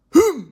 Hnngh.ogg